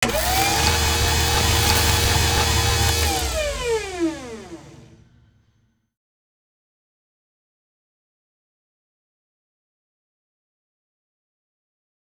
recharge.wav